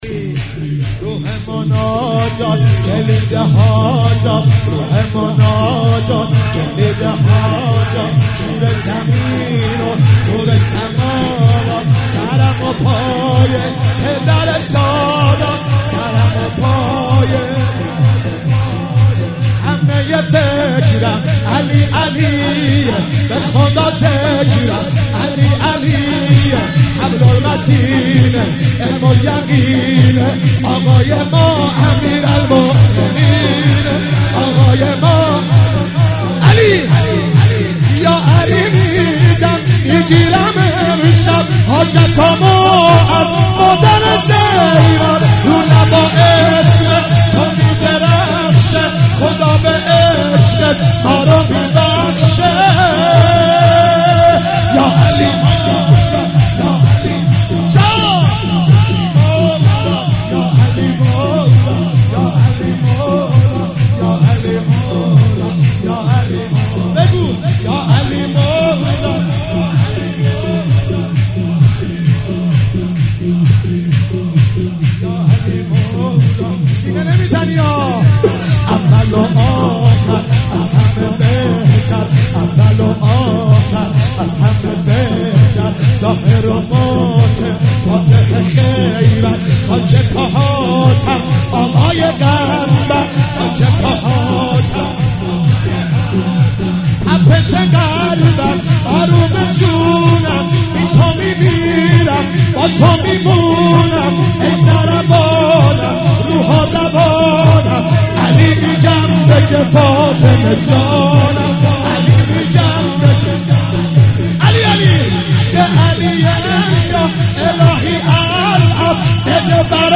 زمینه مداحی